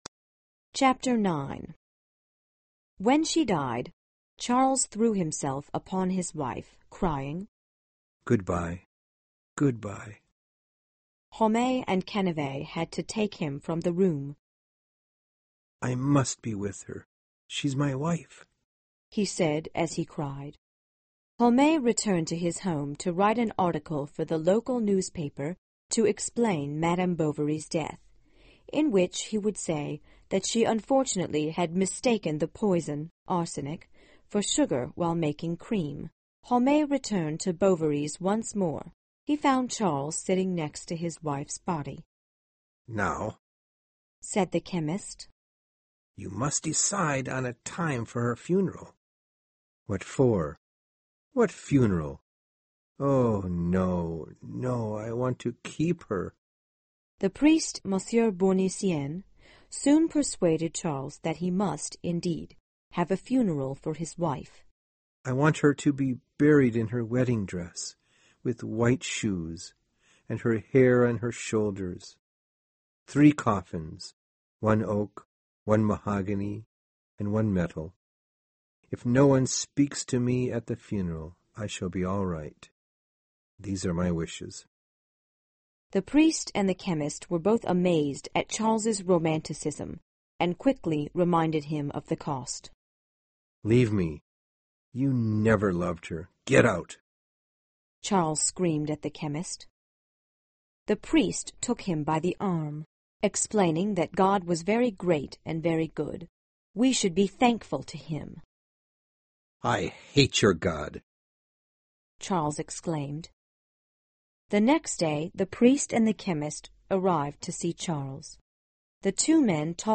有声名著之包法利夫人 309 听力文件下载—在线英语听力室